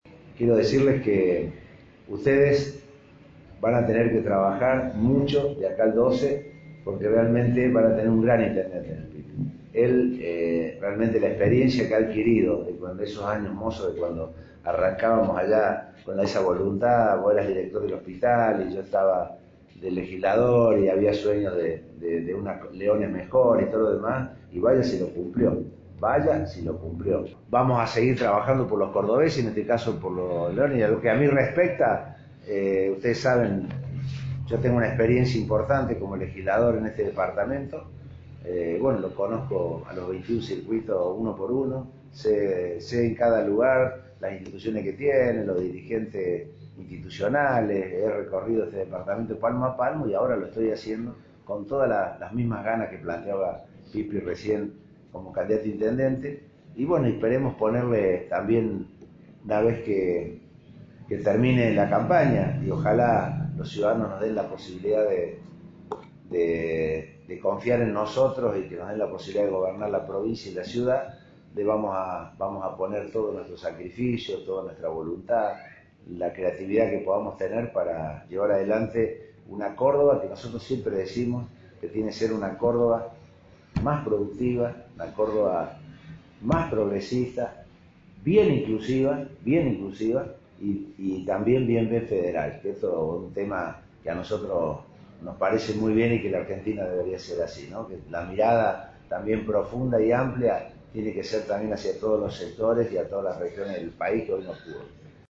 Massei encabezó reunión en el PJ Leones